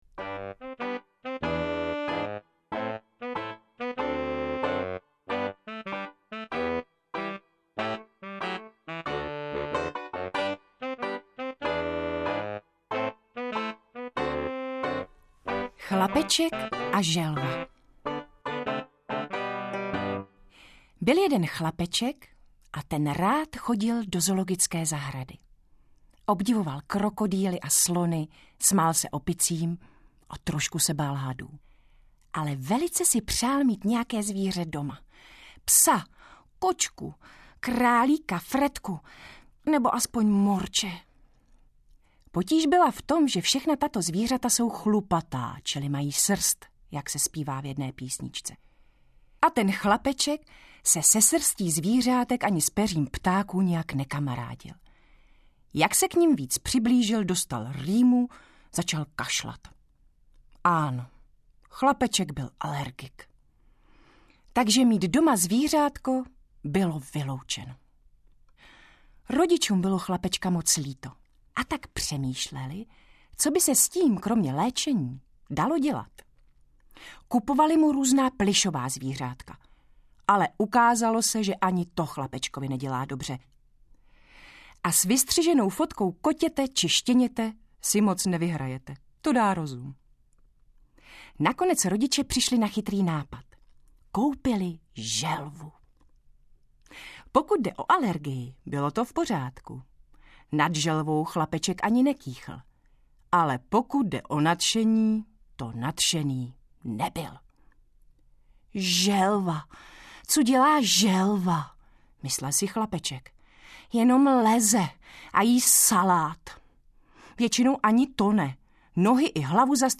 Nezbedníci audiokniha
Ukázka z knihy
• InterpretJan Dolanský, Lenka Vlasáková